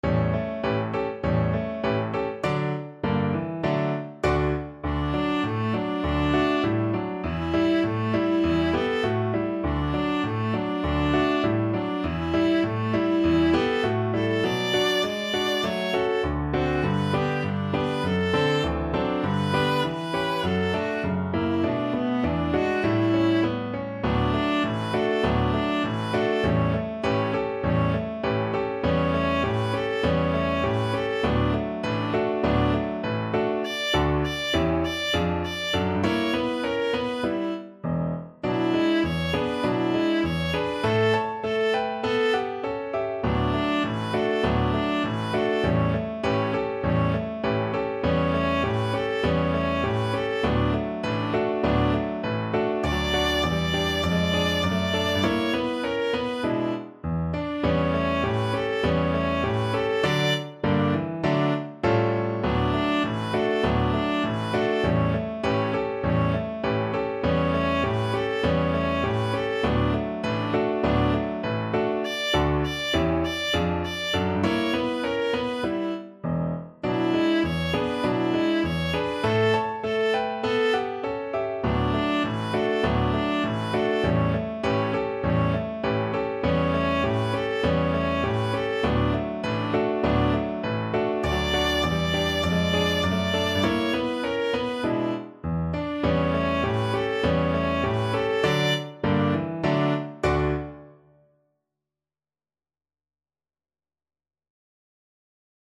Moderato =c.100
2/2 (View more 2/2 Music)
Pop (View more Pop Viola Music)